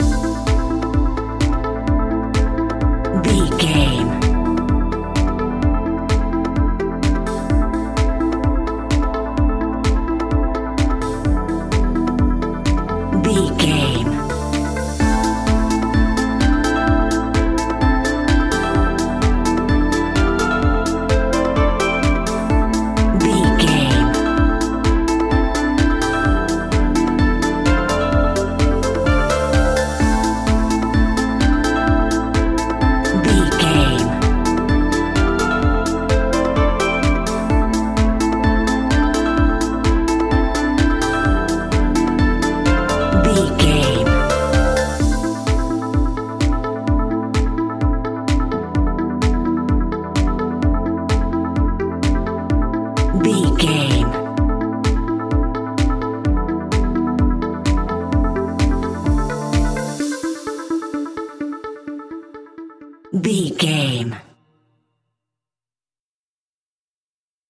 Aeolian/Minor
dark
futuristic
groovy
synthesiser
drum machine
electro house
funky house
synth leads
synth bass